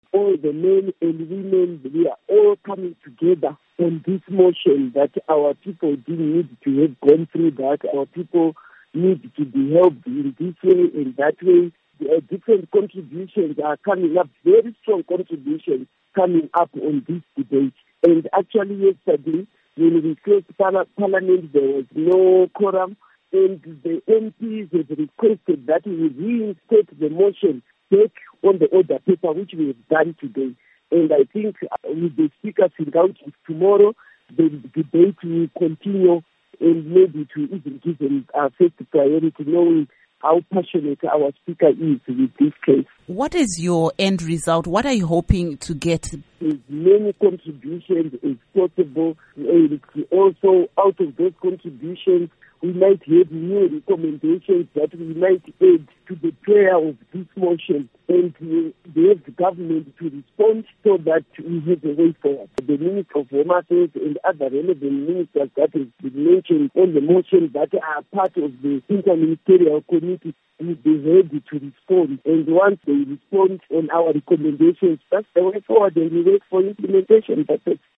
Interview With Biata Beatrice Nyamupinga